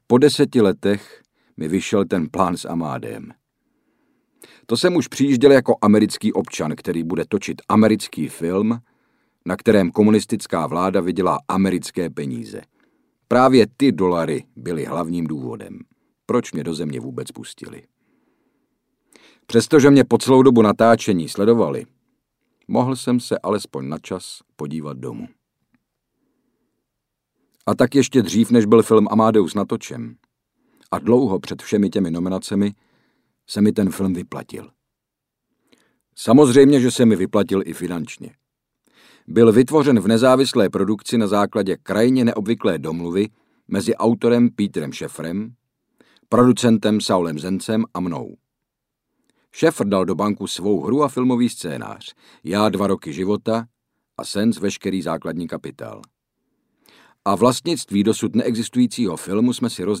Audiobook
Read: Hynek Čermák